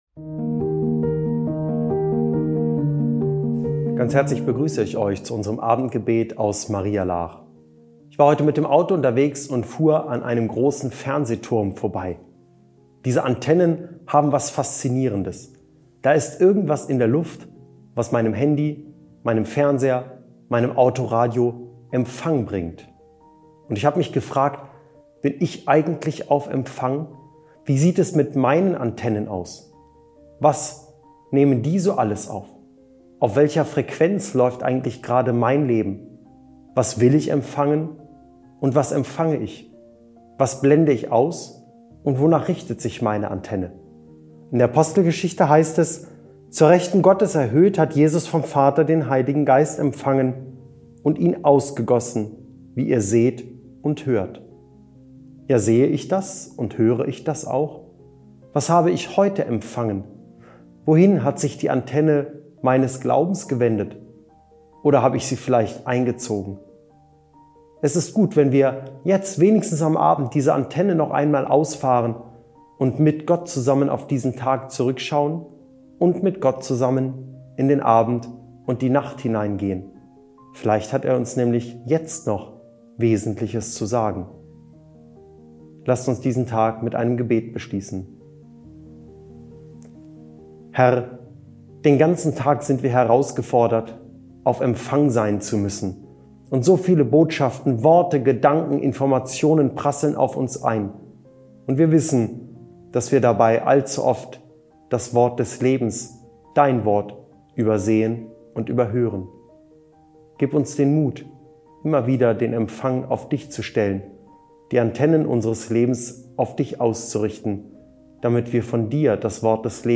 Abendgebet